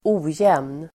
Uttal: [²'o:jem:n]